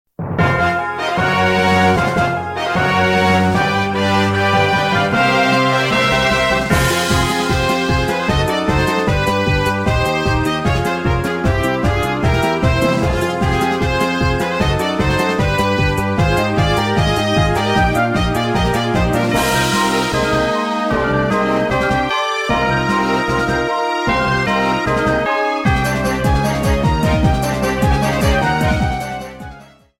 30 seconds and fadeout You cannot overwrite this file.